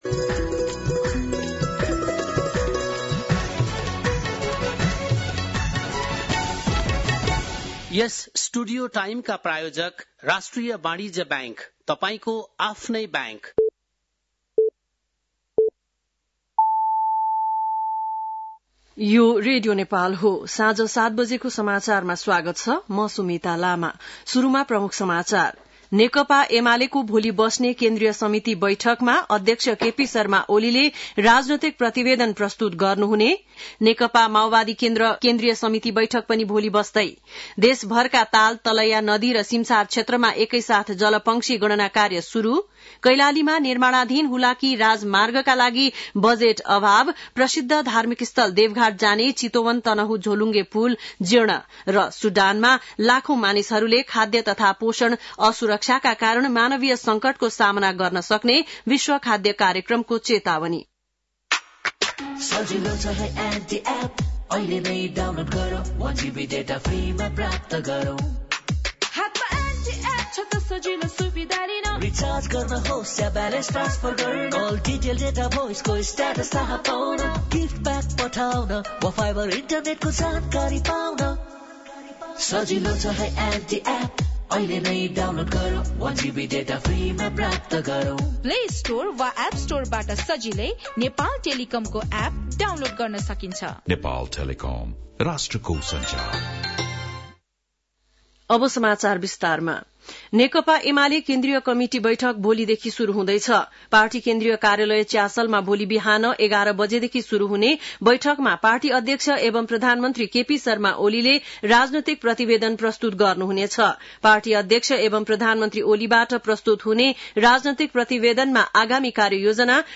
बेलुकी ७ बजेको नेपाली समाचार : २१ पुष , २०८१